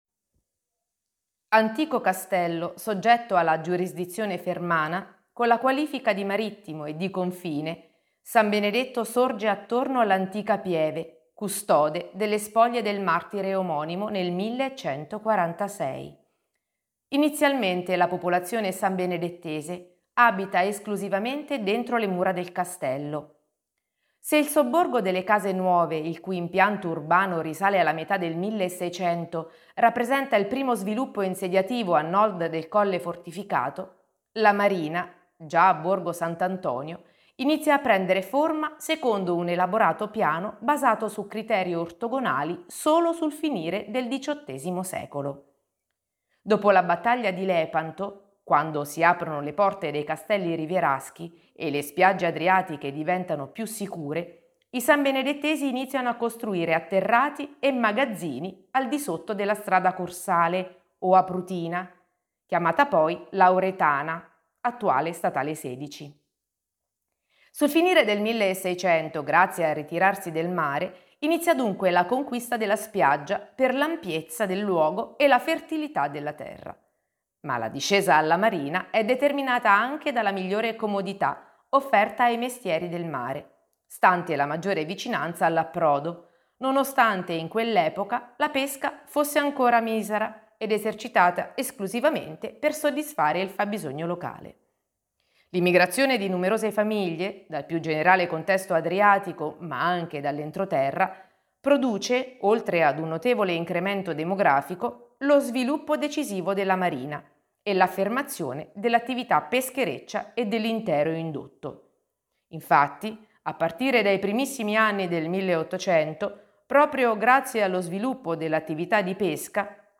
RIPRODUCI L'AUDIOGUIDA COMPLETA RIPRODUCI FERMA oppure LEGGI LA STORIA GUARDA IL VIDEO LIS